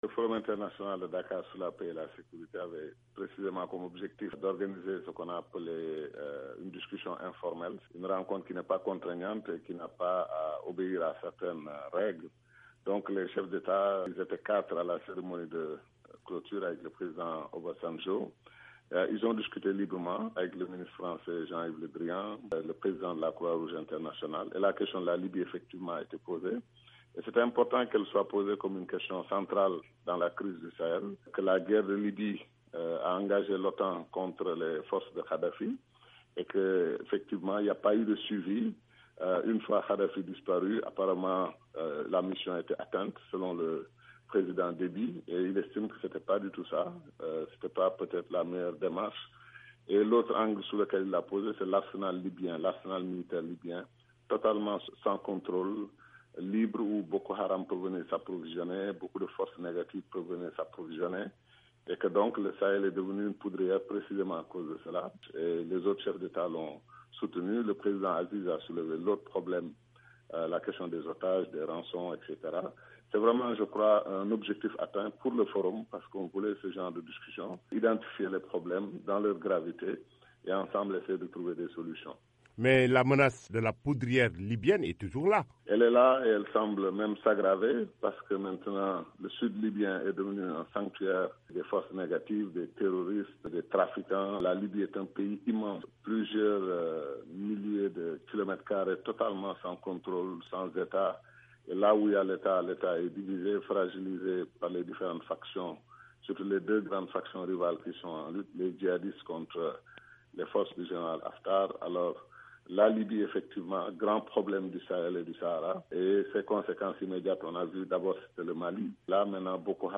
La VOA a joint l'organisateur du premier Forum international sur la paix et la sécurité en Afrique, M. Cheikh Tidiane Gadio, qui nous en a livré le résumé.